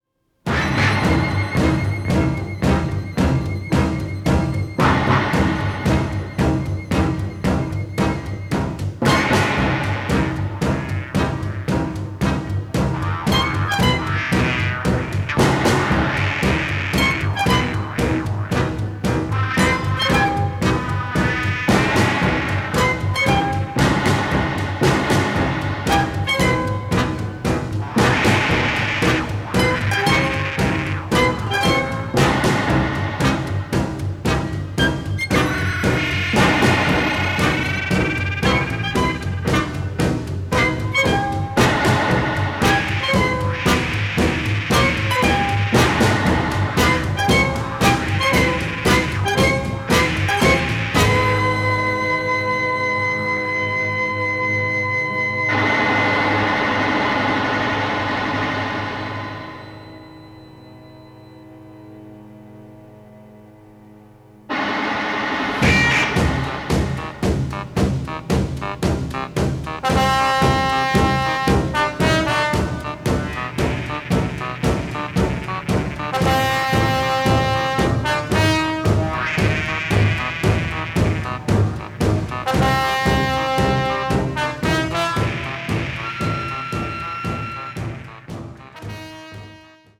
Film Versions (mono)